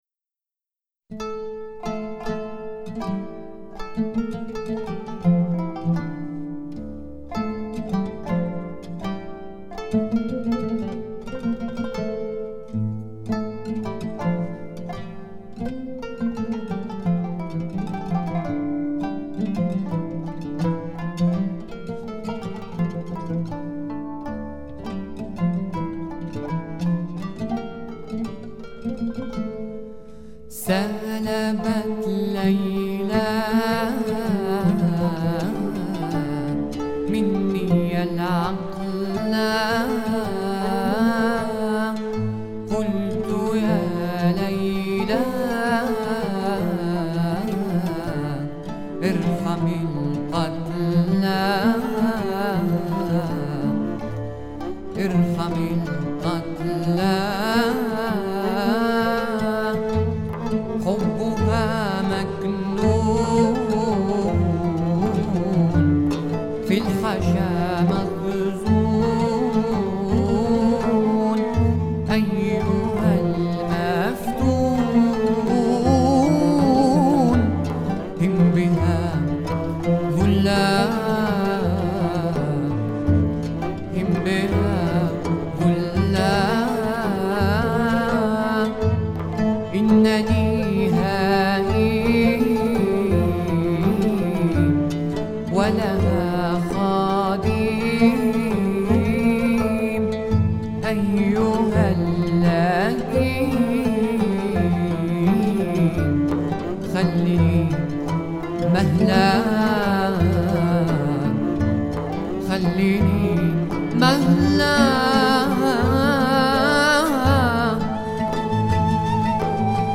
Sufi music for your enjoyment.